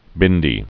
(bĭndē)